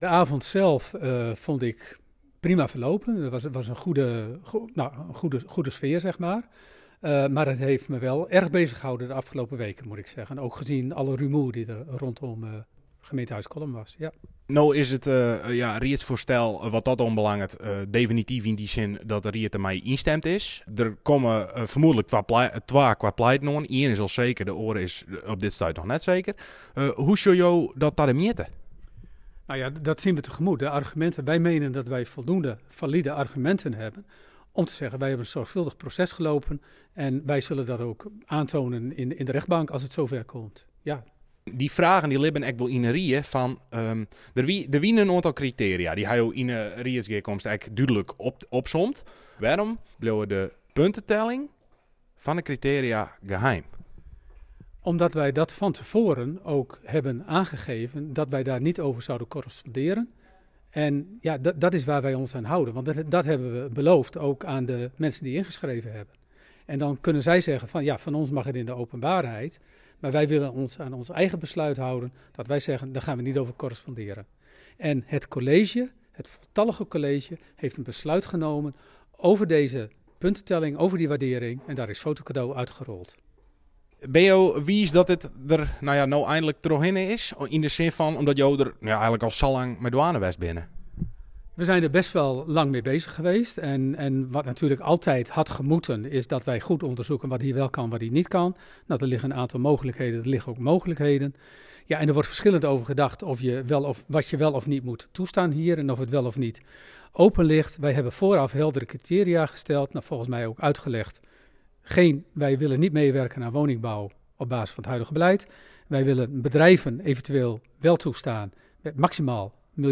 Wethouder Theo Berends